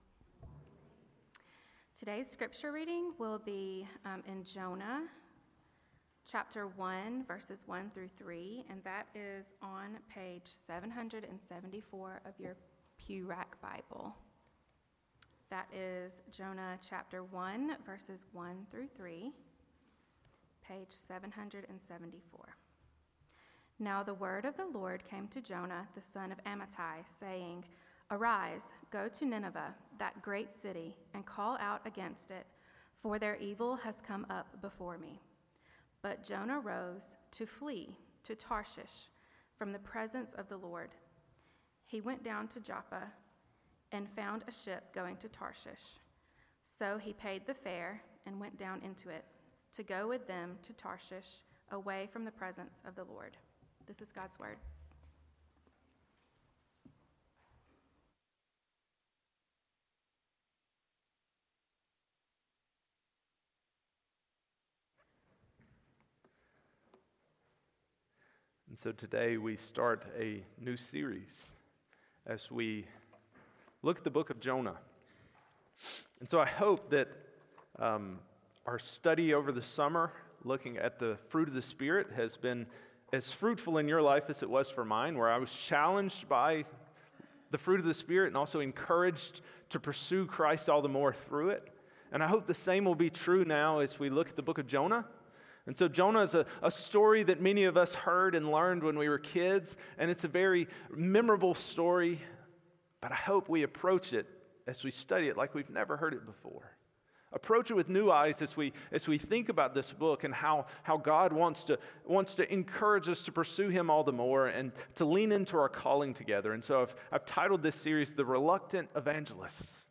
Passage: Jonah 1:1-3 Sermon